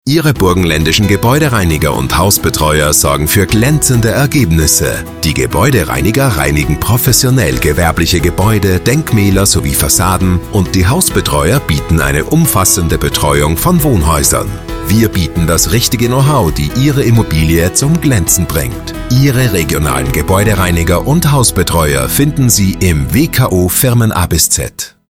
Radiospots der Landesinnung
radiospot-gebaeudereiniger-hausbetreuer.mp3